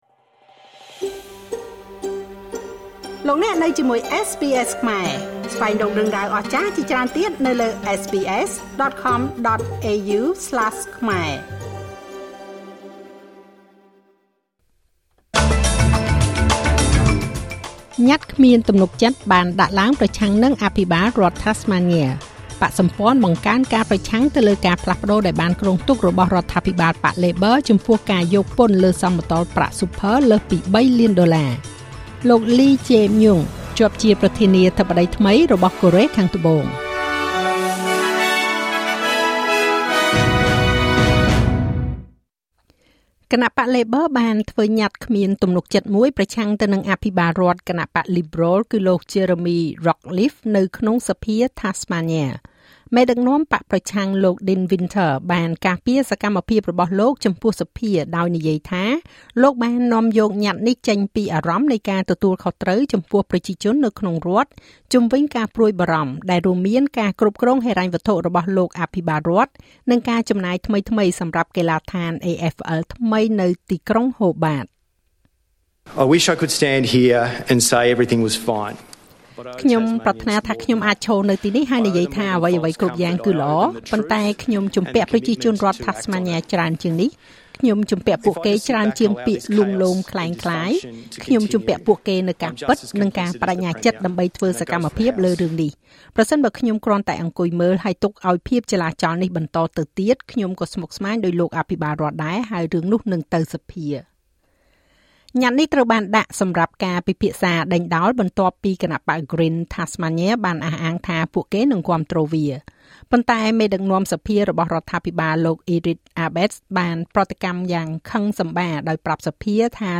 នាទីព័ត៌មានរបស់SBSខ្មែរ សម្រាប់ថ្ងៃពុធ ទី៤ ខែមិថុនា ឆ្នាំ២០២៥